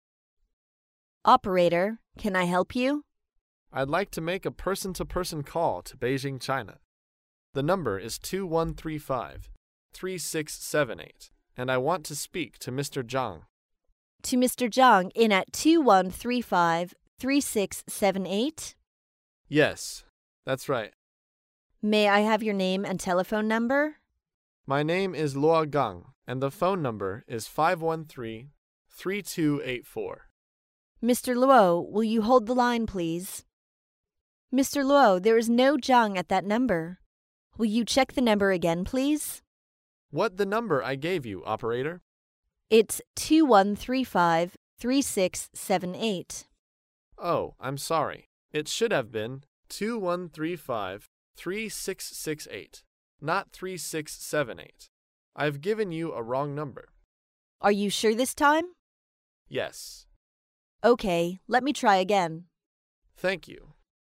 在线英语听力室高频英语口语对话 第377期:报错电话号码(1)的听力文件下载,《高频英语口语对话》栏目包含了日常生活中经常使用的英语情景对话，是学习英语口语，能够帮助英语爱好者在听英语对话的过程中，积累英语口语习语知识，提高英语听说水平，并通过栏目中的中英文字幕和音频MP3文件，提高英语语感。